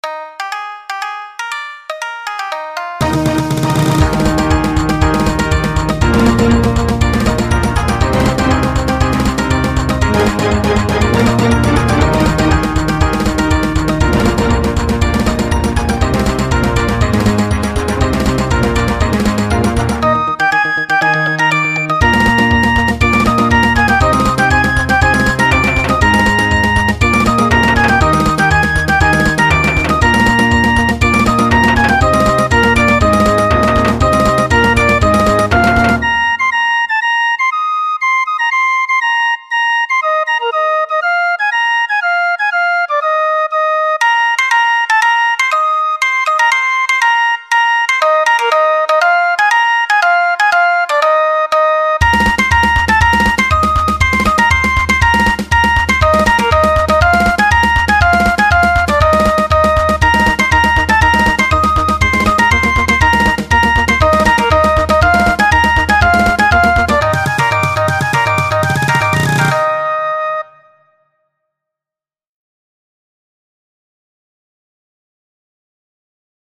沖縄の音階を基に作られた某ゲームの曲を自分なりのｳﾞｧｰｼﾞｮﾝでおとどけすますた（工事中）